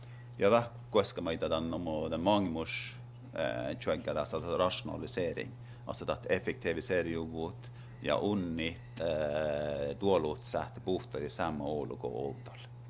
ref_male.wav